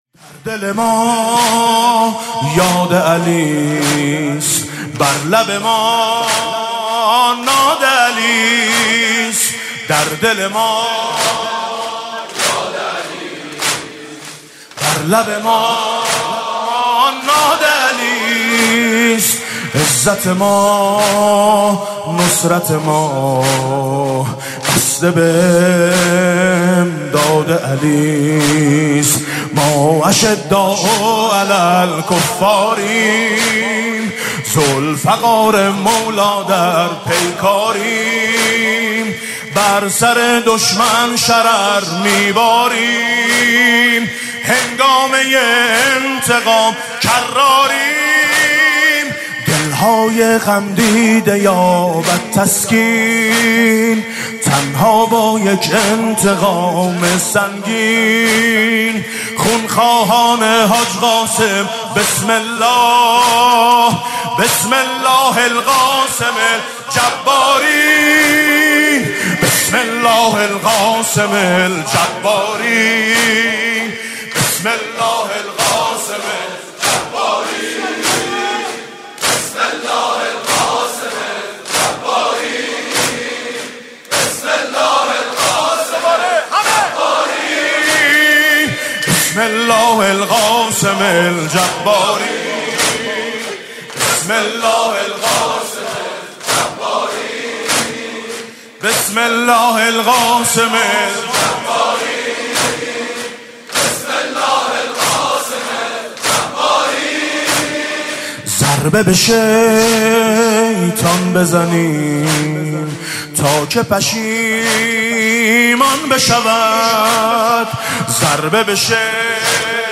مداحی حماسی